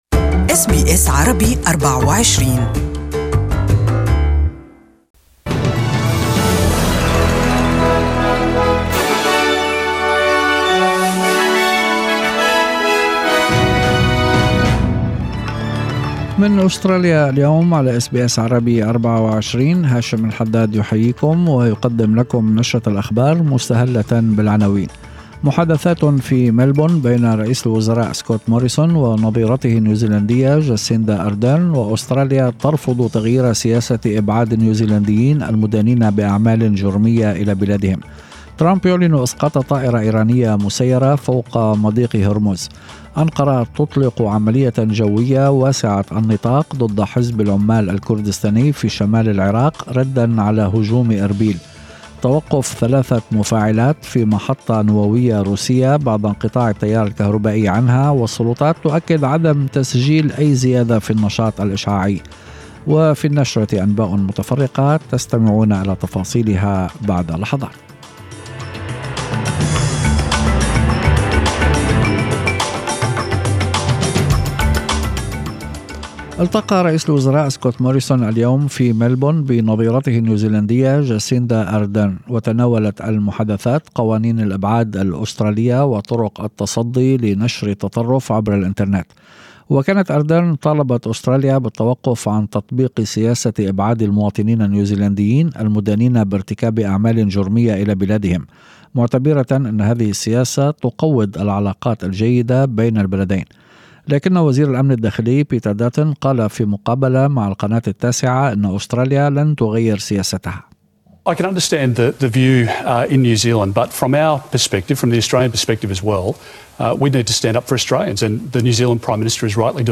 Evening News